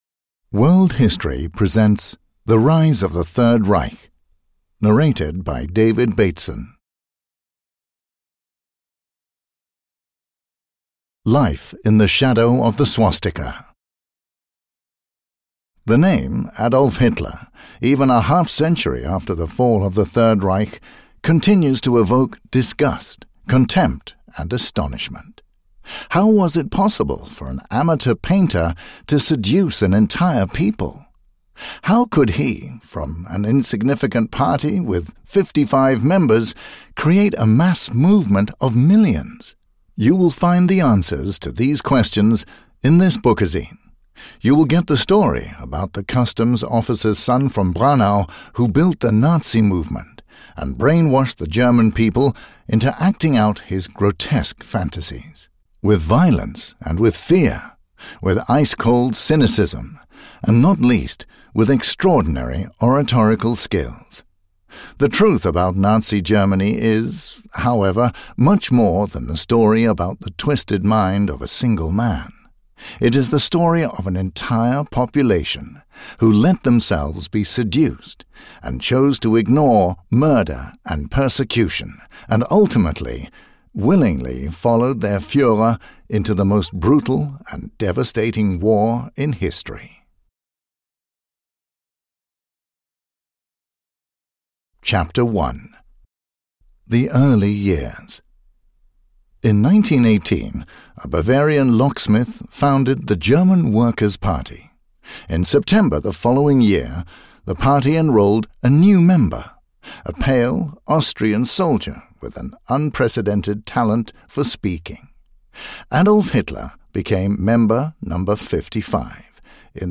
Audio knihaThe Rise of the Third Reich (EN)
Ukázka z knihy